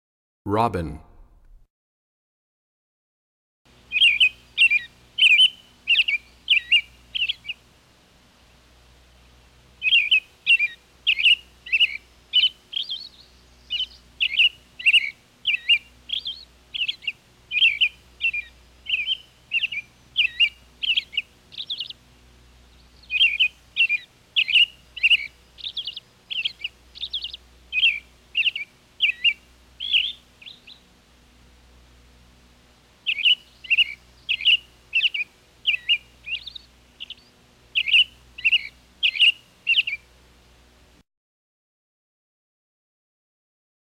73 Robin.mp3